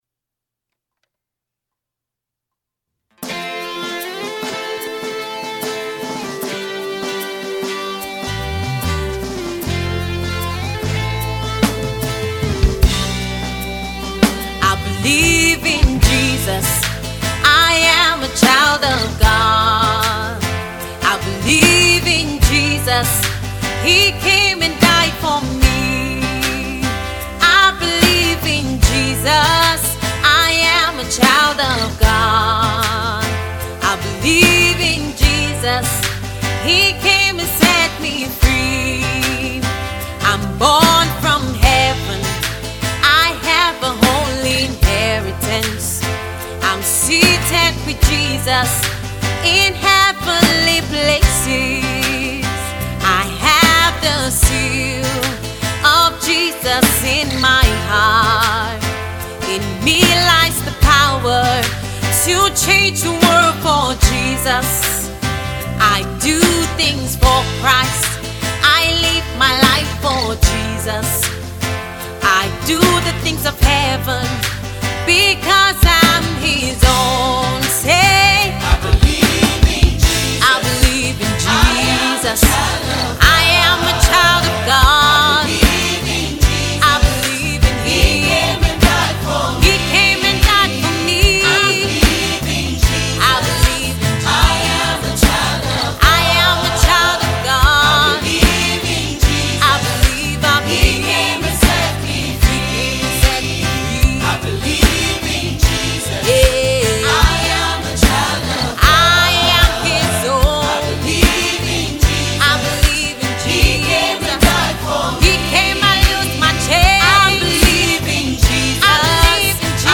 Gospel music artist